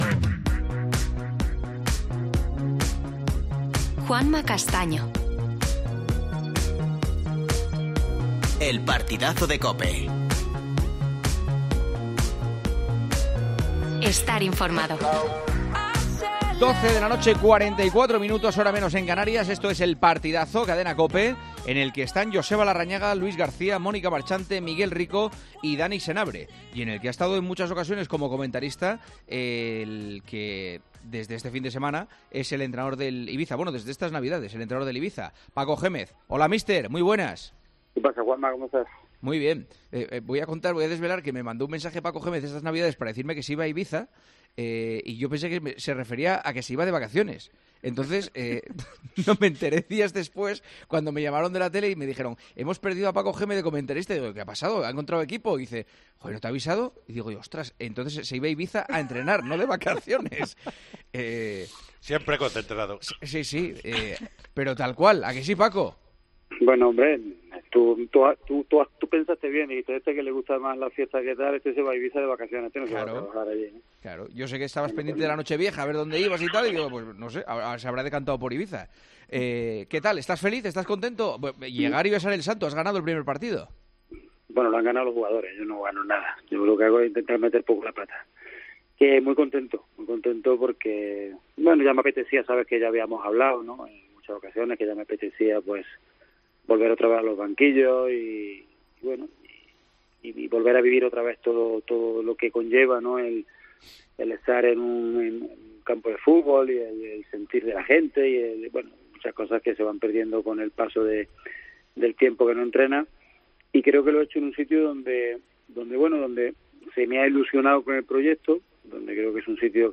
AUDIO: Hablamos en El Partidazo de COPE con el nuevo entrenador del Ibiza, que debutó este fin de semana con una victoria.